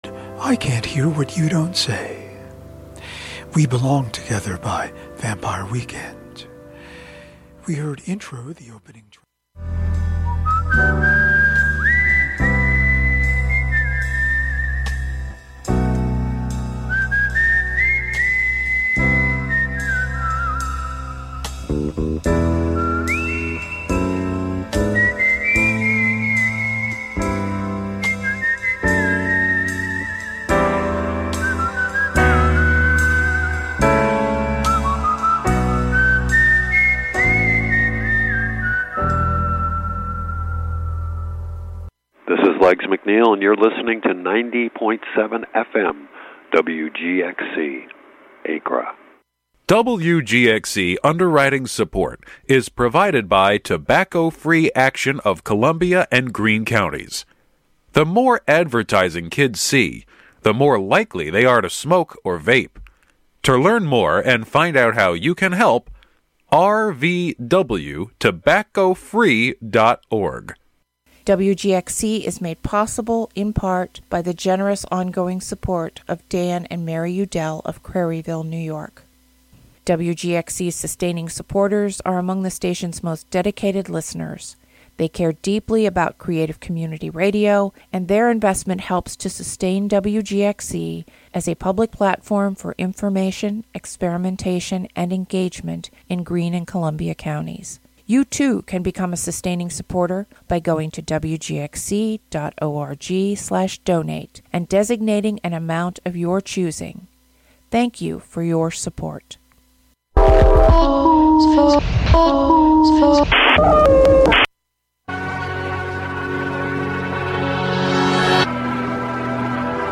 An original radio theatre show each week, with most sounds culled from the previous week, about a fictional, theatrical presidency. Contradictions tell the story, with songs, skits, and clips from political news shows and late-night comedy routines.